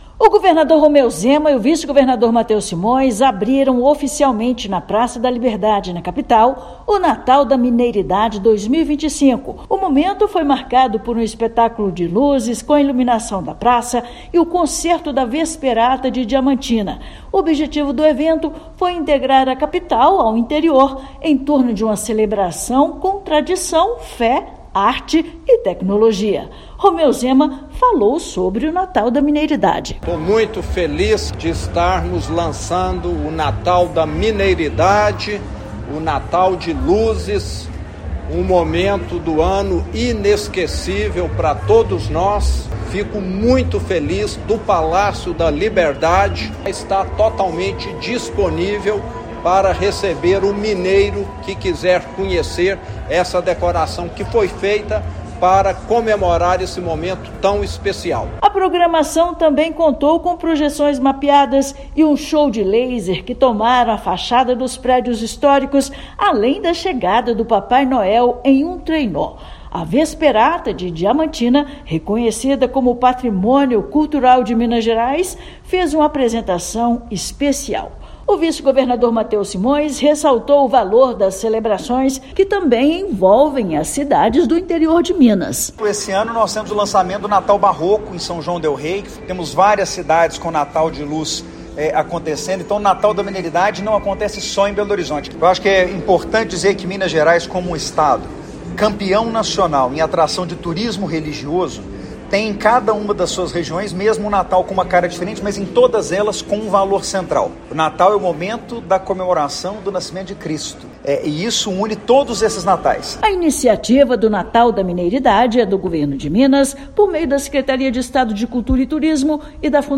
Com música, projeções mapeadas, lasers e show de drones, evento convidou o público a vivenciar momentos únicos e consolidou Minas como destino de Natal mais completo do país. Ouça matéria de rádio.